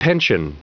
Prononciation du mot pension en anglais (fichier audio)
Prononciation du mot : pension